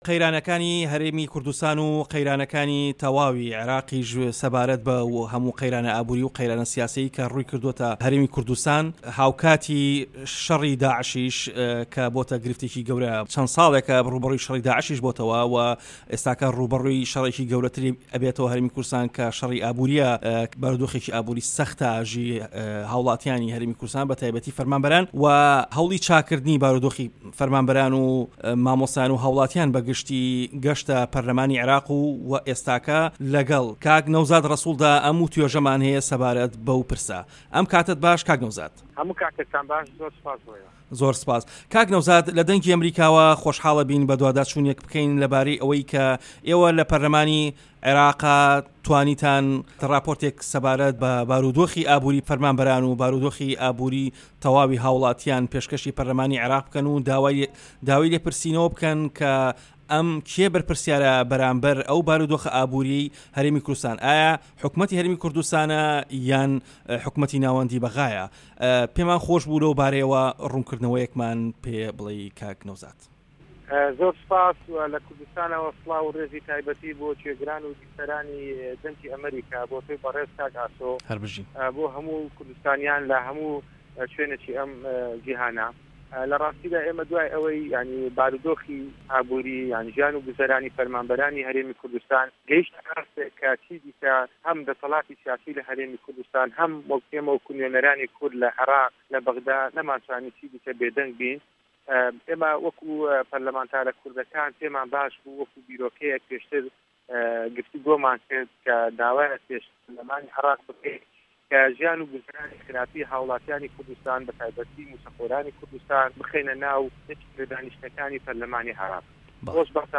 وتووێژ لەگەڵ نەوزاد ڕەسوڵ